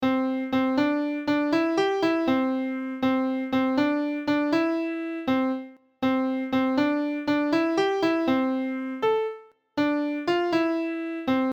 The melody to the traditional song "Pop Goes the Weasel"